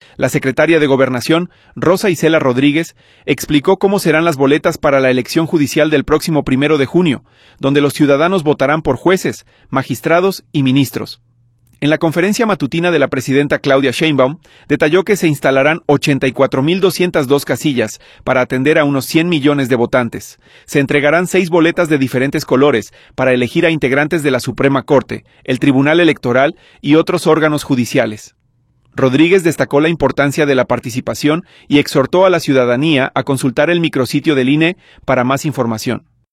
La secretaria de Gobernación, Rosa Icela Rodríguez, explicó cómo serán las boletas para la elección judicial del próximo 1 de junio, donde los ciudadanos votarán por jueces, magistrados y ministros. En la conferencia matutina de la presidenta Claudia Sheinbaum, detalló que se instalarán 84,202 casillas para atender a unos 100 millones de votantes. Se entregarán seis boletas de diferentes colores para elegir a integrantes de la Suprema Corte, el Tribunal Electoral y otros órganos judiciales.